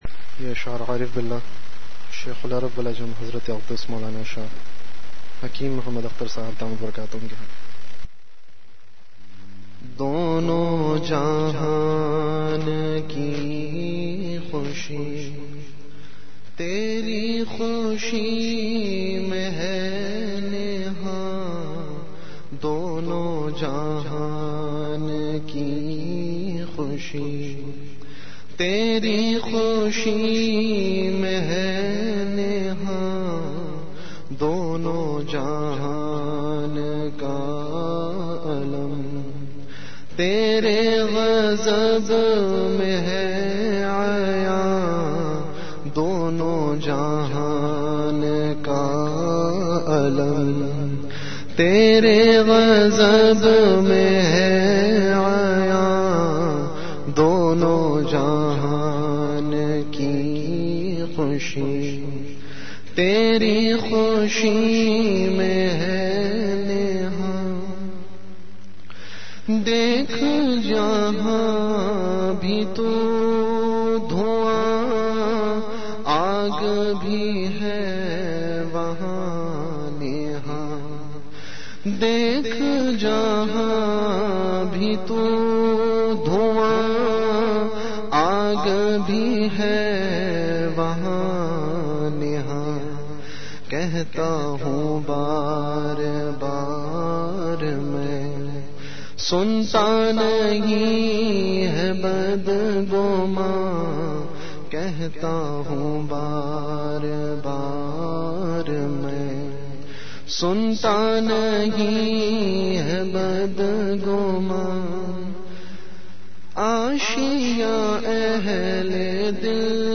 Majlis-e-Zikr · Home Majlis e Zikr(09-Jan-2011